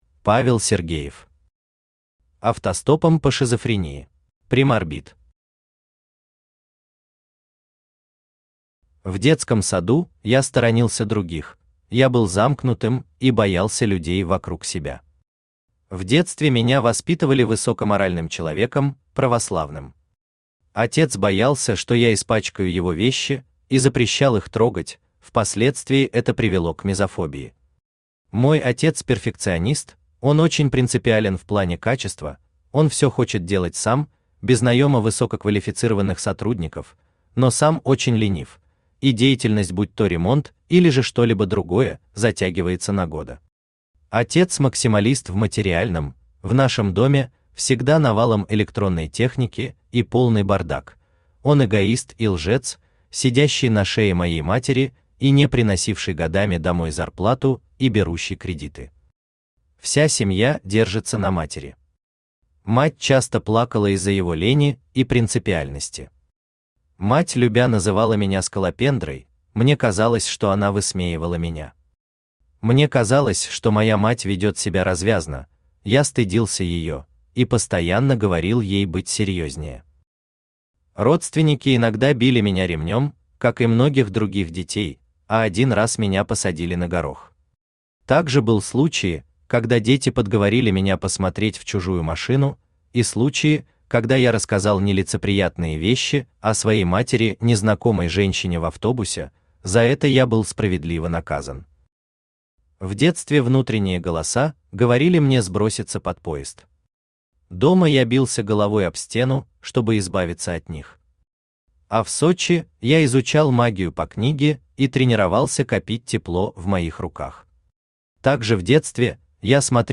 Aудиокнига Автостопом по шизофрении Автор Павел Александрович Сергеев Читает аудиокнигу Авточтец ЛитРес. Прослушать и бесплатно скачать фрагмент аудиокниги